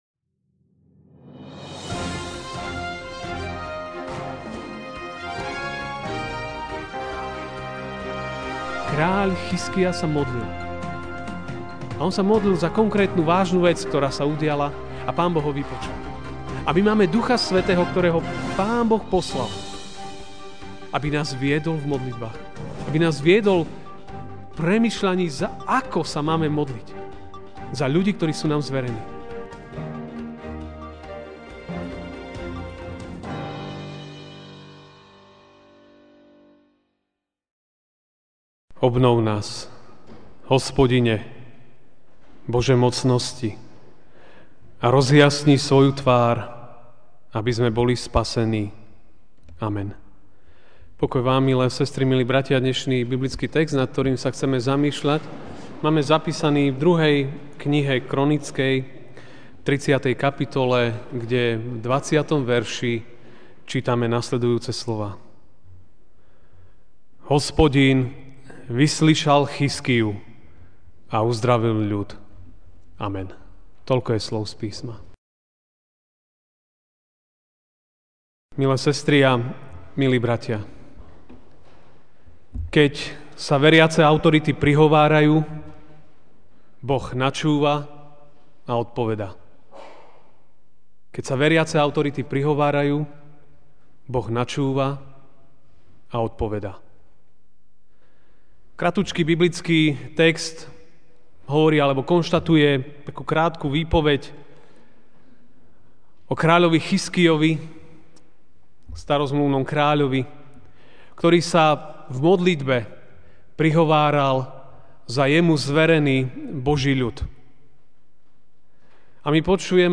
MP3 SUBSCRIBE on iTunes(Podcast) Notes Sermons in this Series Ranná kázeň: Keď sa autority prihovárajú!